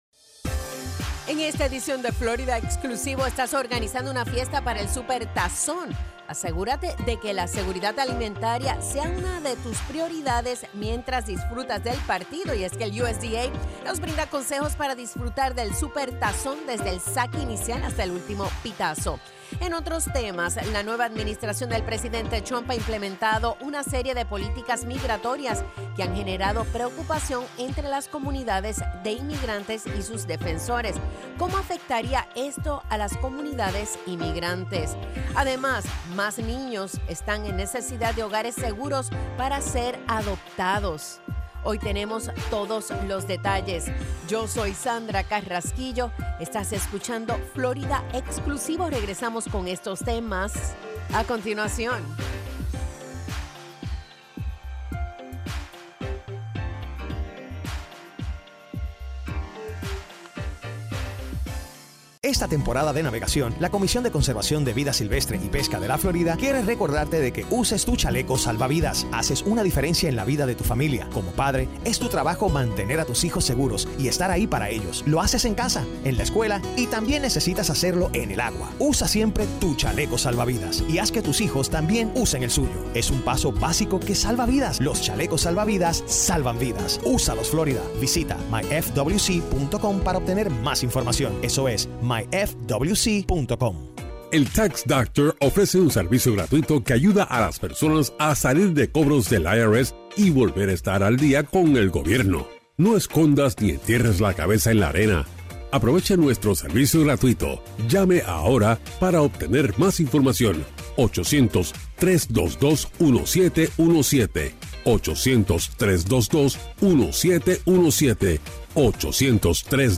FNN's Florida Exclusivo is a weekly, one-hour news and public affairs program that focuses on news and issues of the Latino community.